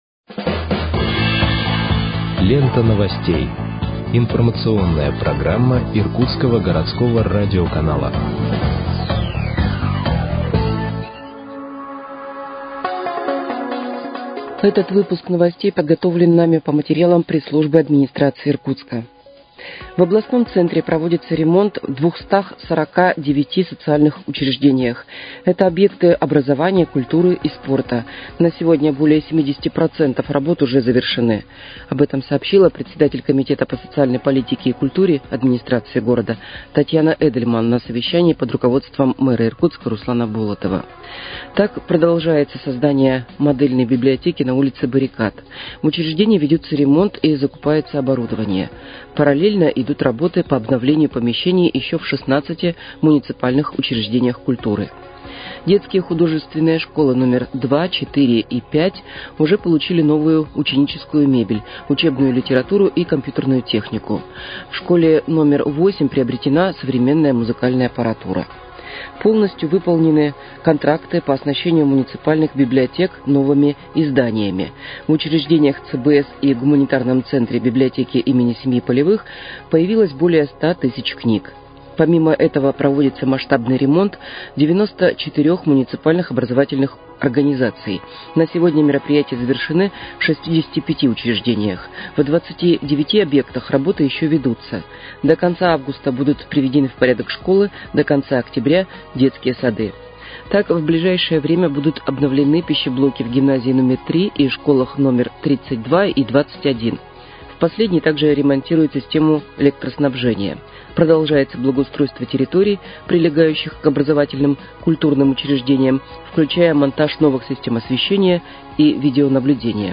Выпуск новостей в подкастах газеты «Иркутск» от 25.07.2025 № 1